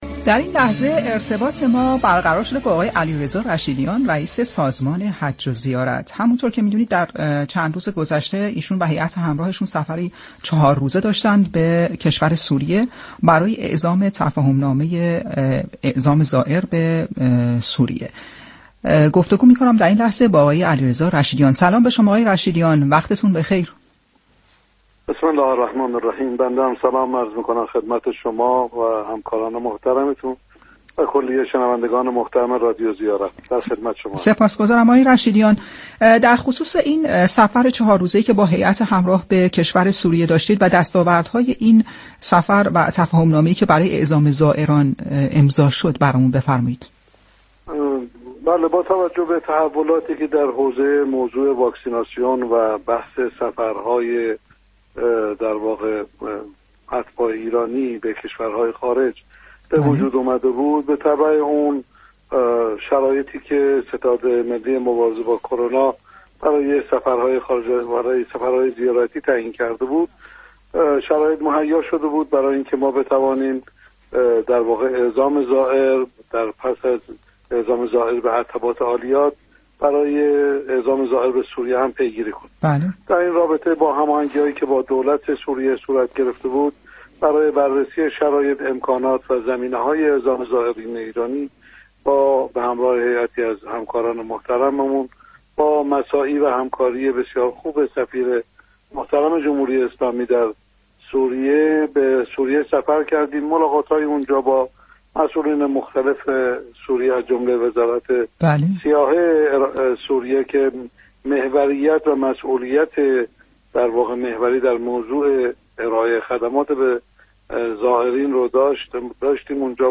به گزارش رادیو زیارت ، علی رضا رشیدیان امروز در گفتگو با این رادیو با اشاره به پایان سفر چهار روزه به سوریه اظهار داشت: با توجه به گسترش خوب واکسیناسیون و موافقت ستاد ملی کرونا با برقراری سفرهای زیارتی ؛ سازمان حج و زیارت که وظیفه برقراری و مدیریت این سفرها را برعهده دارد ، برنامه ریزی و پیگیری برای ازسرگیری سفرهای زیارتی را در دستور کار خود قرار داد.